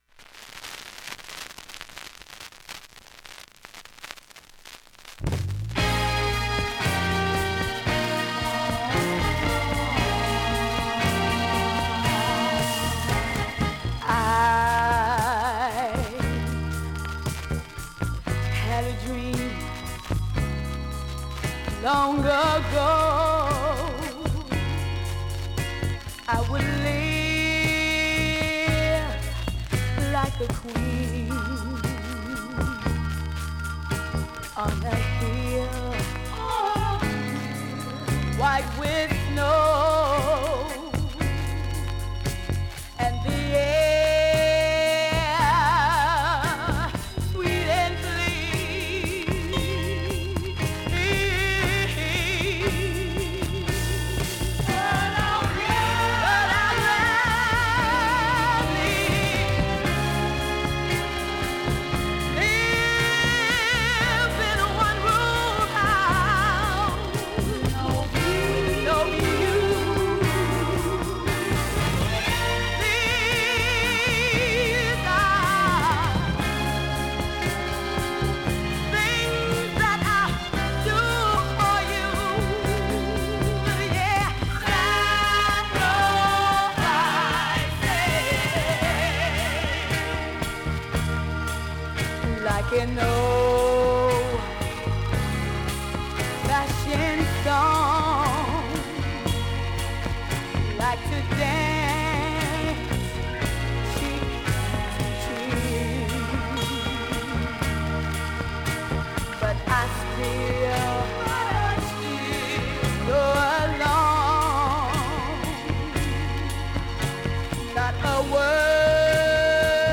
若干バックノイズあり